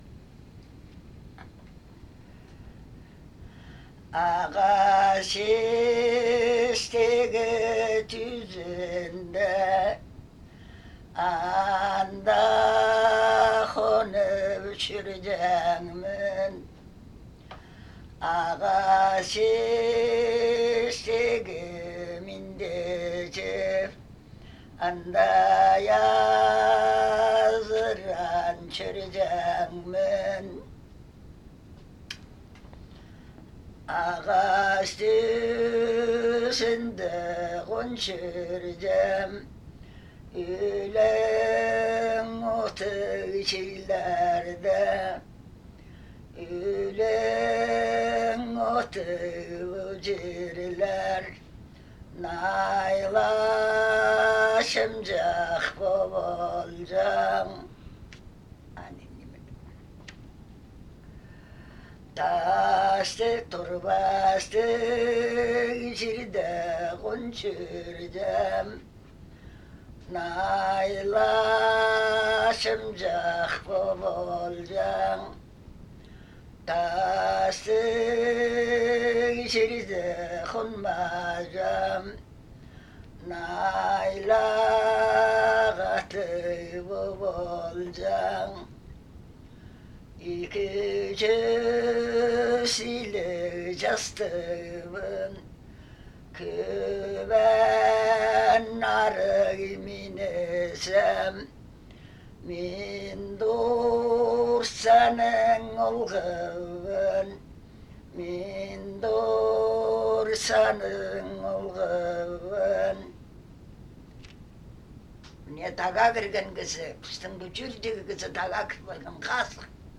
Необрядовые песни и песнопения шаманского репертуара
отличается от обычного пения низким и хрипловатым тембром (фарингализацией) и, в особенности, обильным использованием приема вибрато.
Мифологический рассказ полған ниме о тағ кiзiлер («горных людях») с двумя песнями сарын